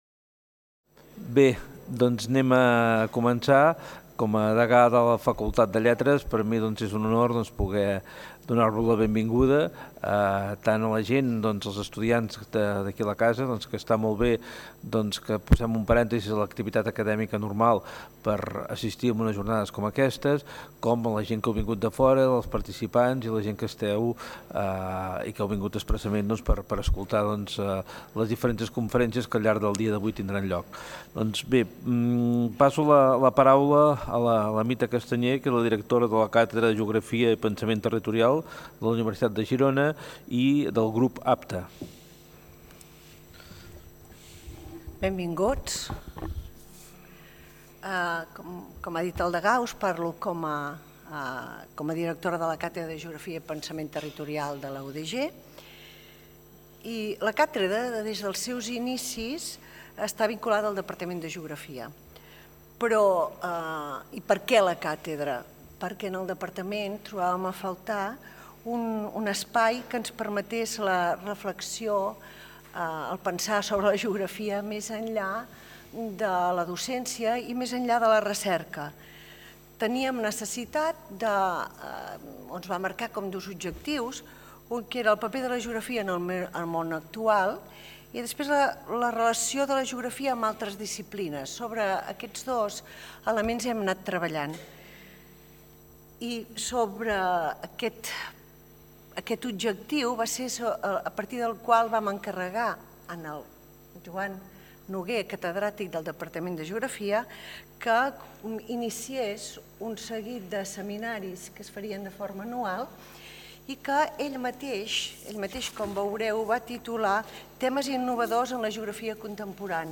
Acte de benvinguda i presentació del Seminari LÍMITS: Marges, Franges, Fronteres